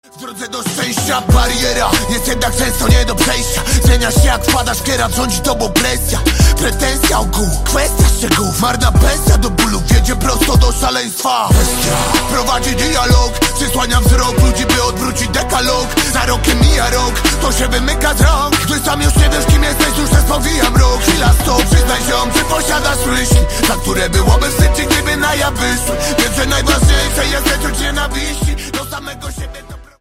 Hip-Hop/Rap